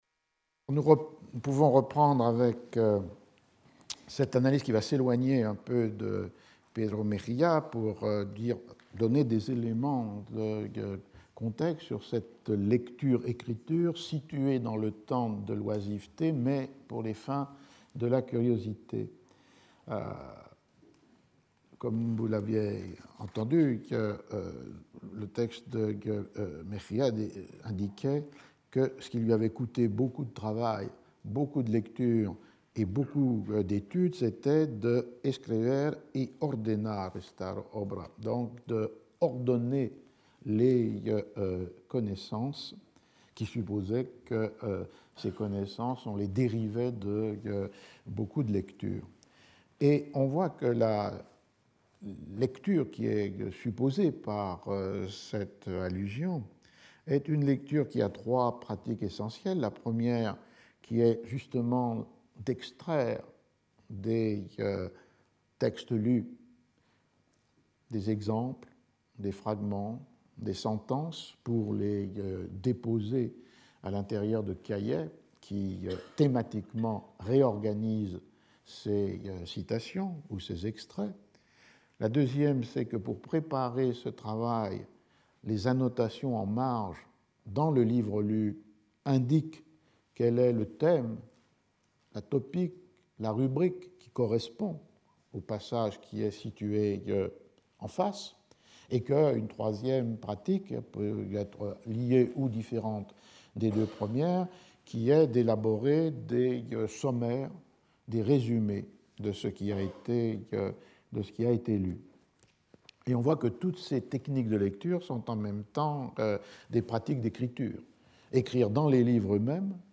Roger Chartier Professeur du Collège de France